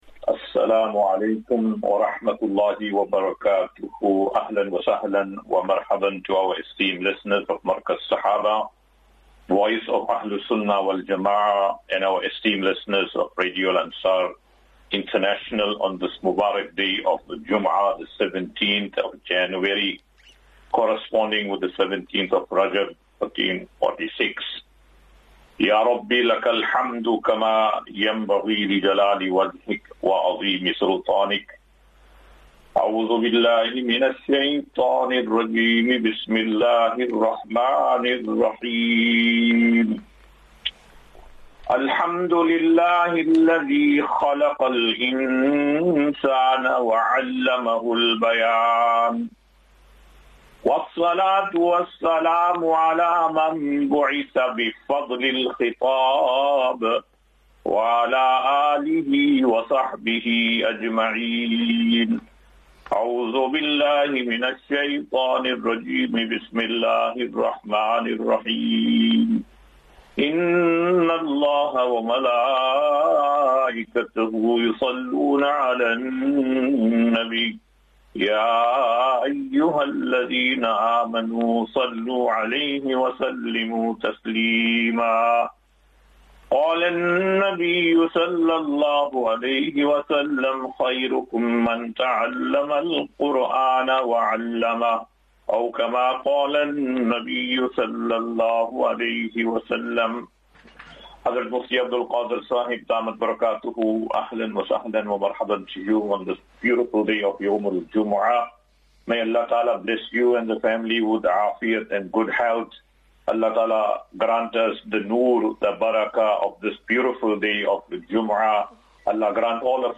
17 Jan 17 January 2025. Assafinatu - Illal - Jannah. QnA
Daily Naseeha.